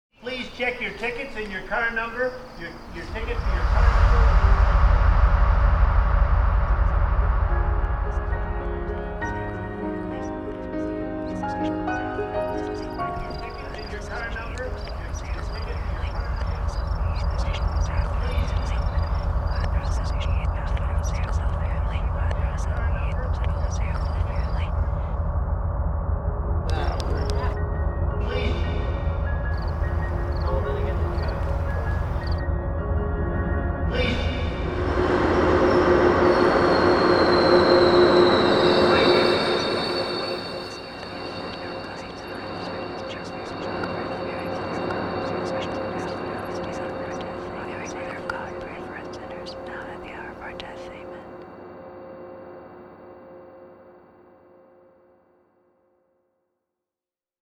Waiting for the train to arrive and take me home, my minidisc player distracts me from reality while enhancing it at the same time.